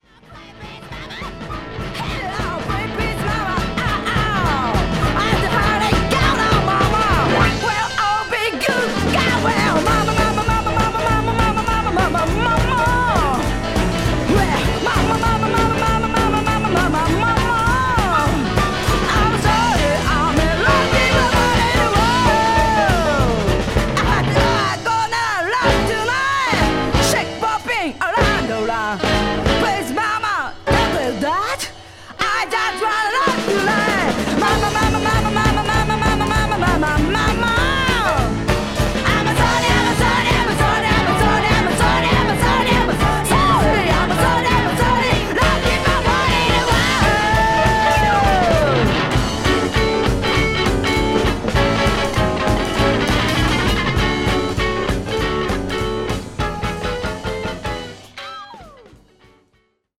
60's Mod/R&B、ガレージ・ソウル、さらにはニューオーリンズ・テイストまで幅広い内容